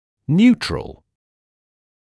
[‘njuːtrəl][‘нйуːтрэл]нейтральный